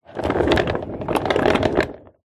Звуки тележки, вагонетки
Маленькая деревянная кукла легко скользит по дереву